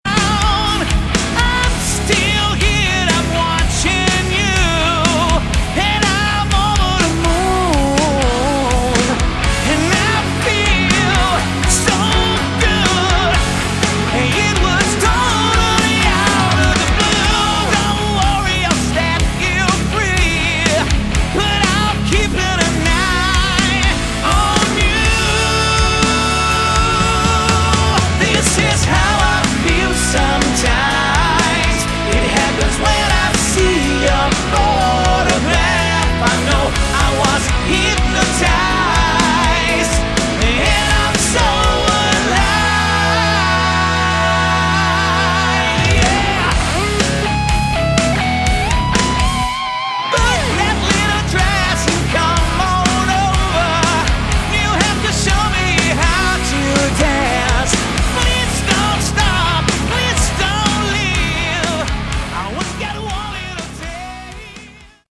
Category: Melodic Hard Rock
Guitars
Drums
Bass
Backing vocals